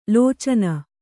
♪ lōcana